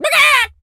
chicken_cluck_scream_long_04.wav